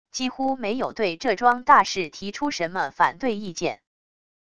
几乎没有对这桩大事提出什么反对意见wav音频生成系统WAV Audio Player